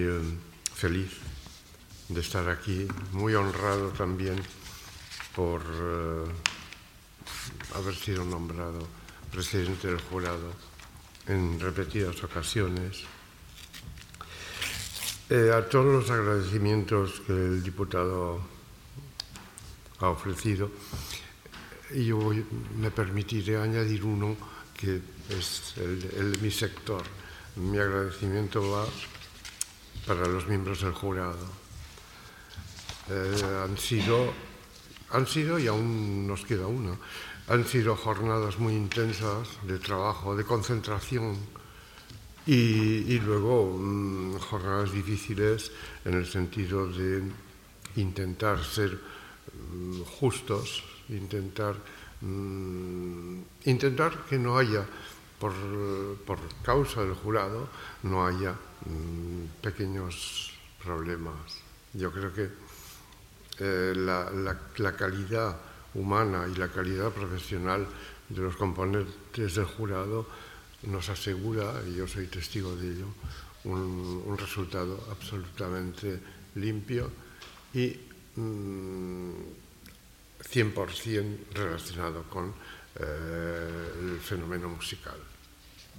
Declaraciones en audio de las autoridades